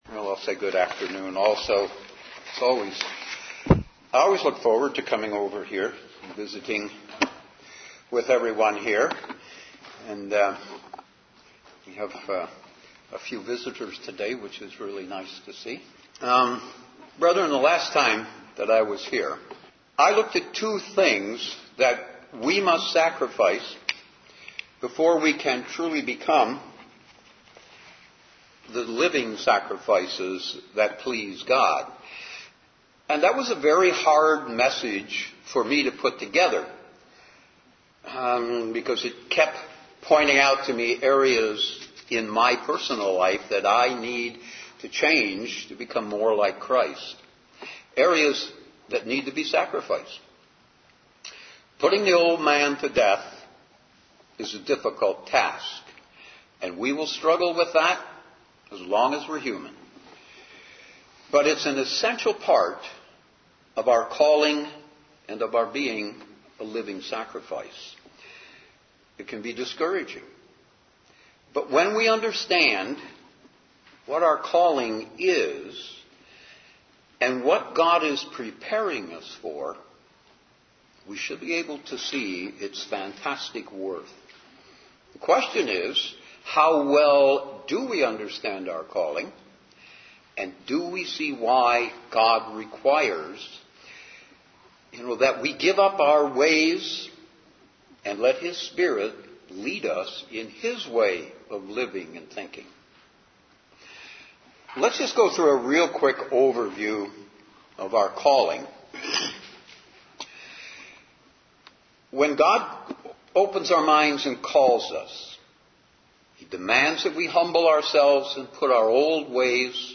Sermons
Given in Detroit, MI